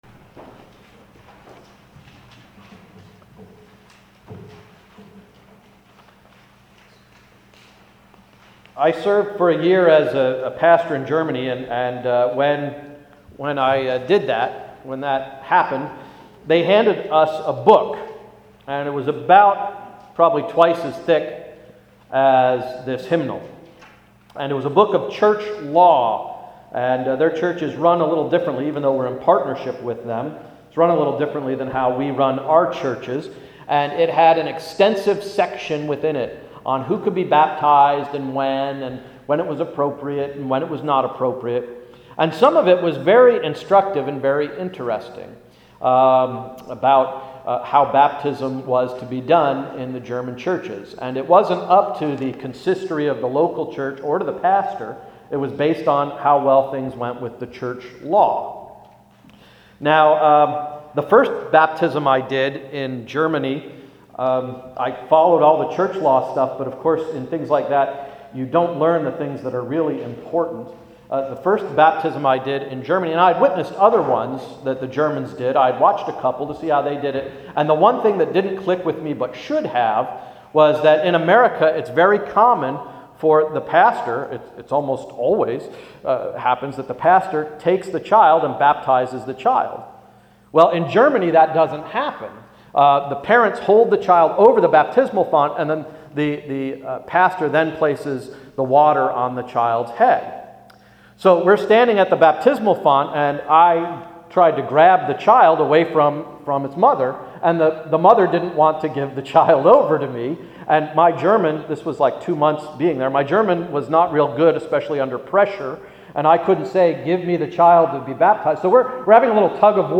Sermon for Sunday January 8–“Throw the Book At Them!”